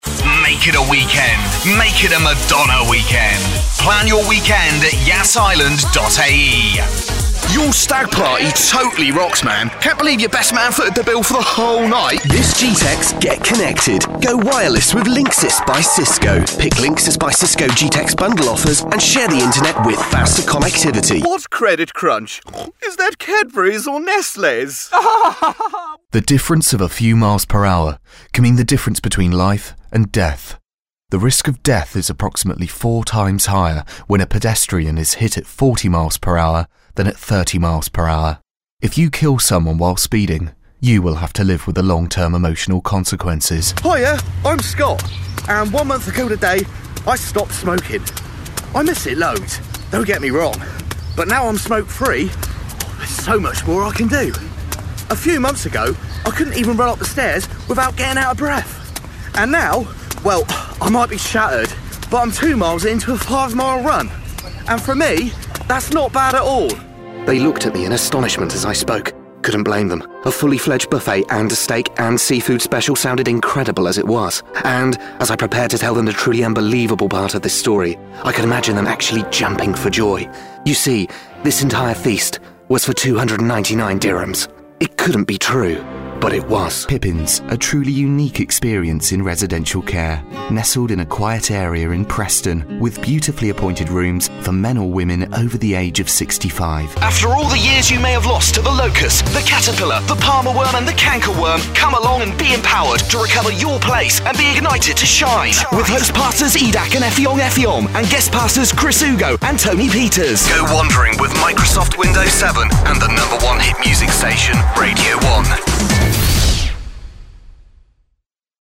Young, Professional, British Male Voice Artist.
britisch
Sprechprobe: Werbung (Muttersprache):